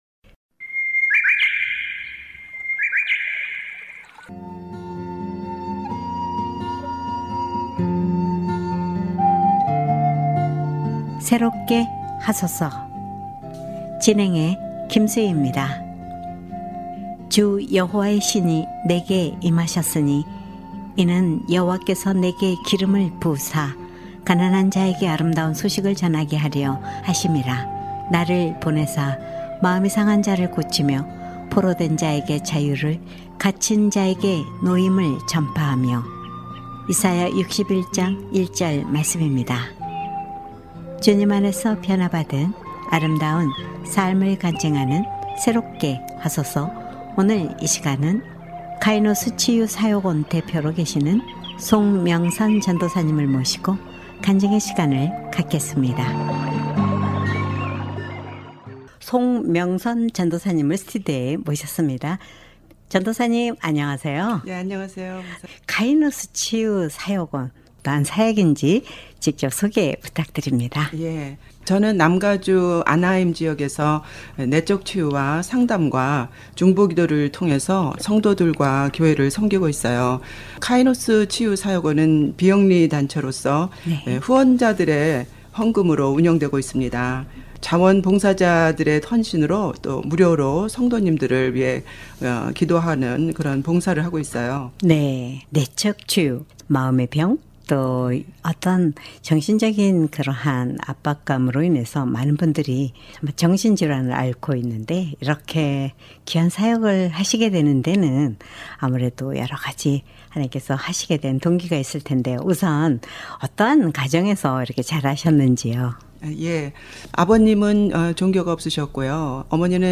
사역자 간증